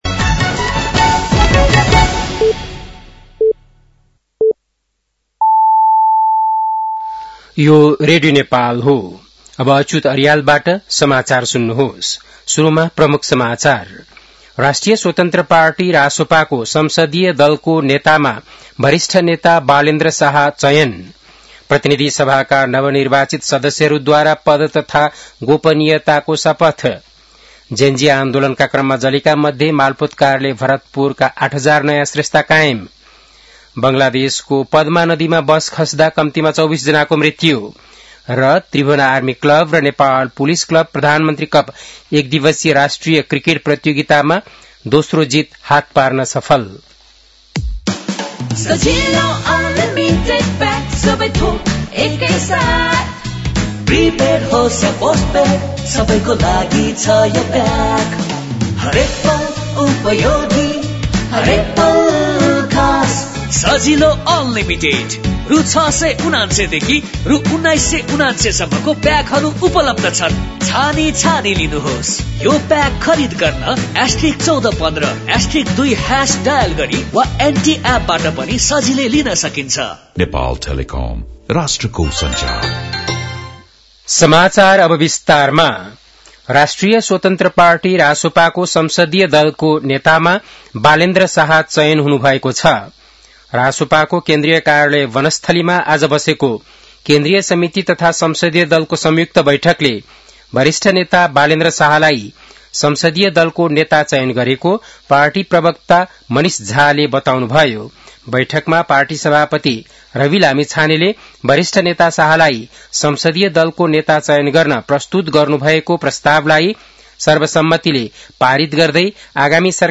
बेलुकी ७ बजेको नेपाली समाचार : १२ चैत , २०८२
7.-pm-nepali-news-1-1.mp3